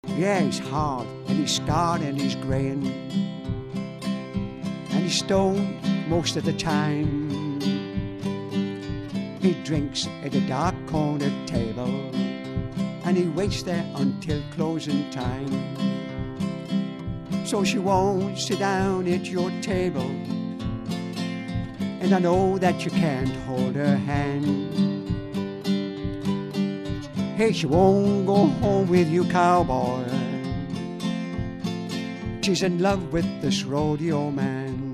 I play acoustic guitar and sing Country & Western songs.